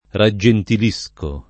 vai all'elenco alfabetico delle voci ingrandisci il carattere 100% rimpicciolisci il carattere stampa invia tramite posta elettronica codividi su Facebook raggentilire v.; raggentilisco [ ra JJ entil &S ko ], ‑sci